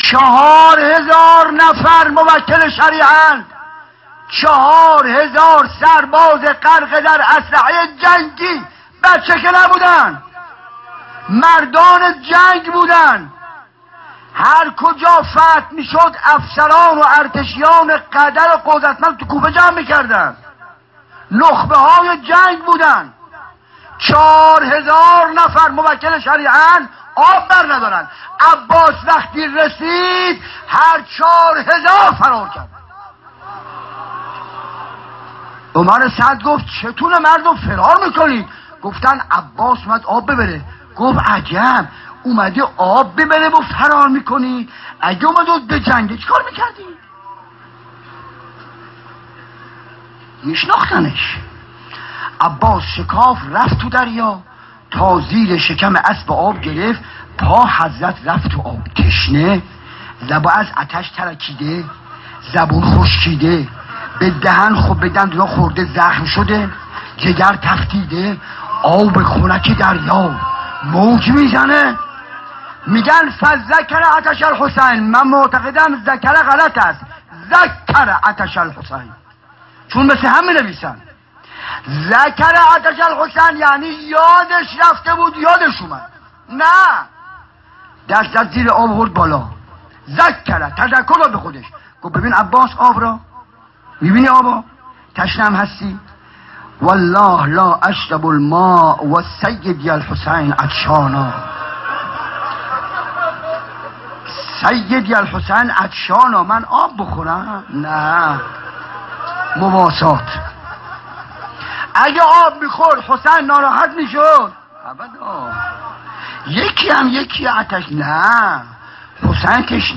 ظهر تاسوعا محرم 96 - کربلای معلی - روضه